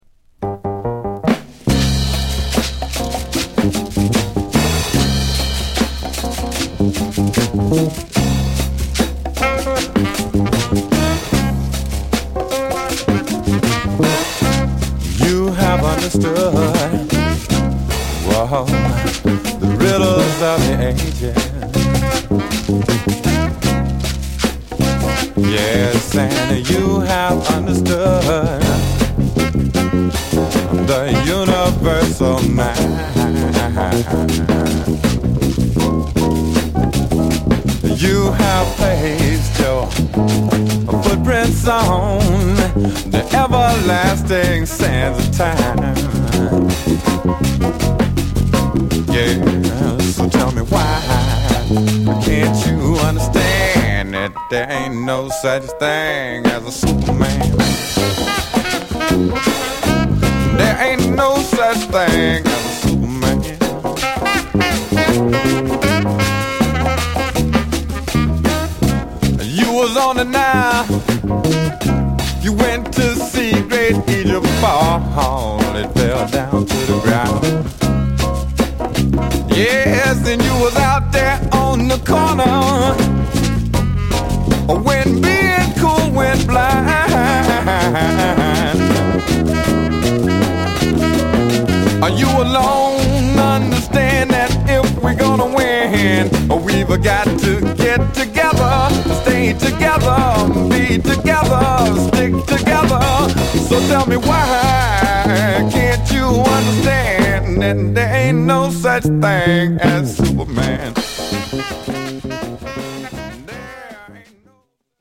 (Stereo)*